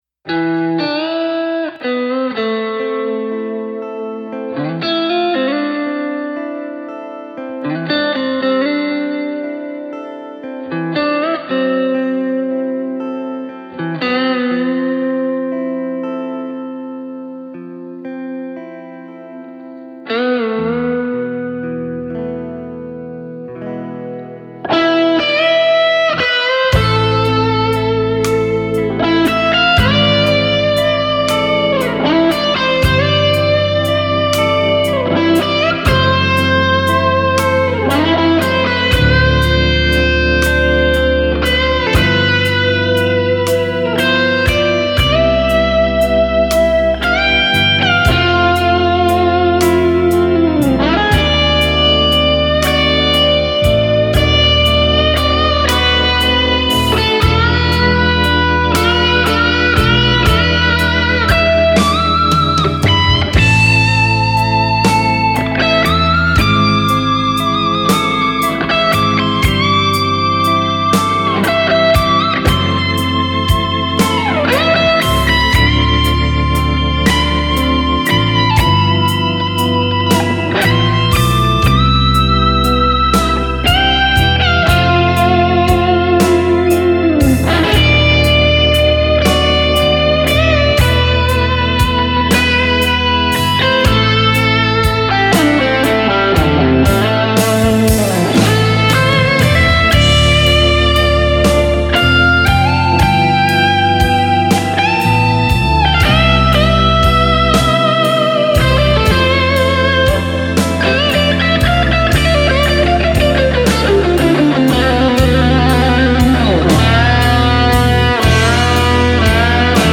Lead Vocals, Backing Vocals, Lead Guitar, Rhythm Guitar
Organ [Hammond B3]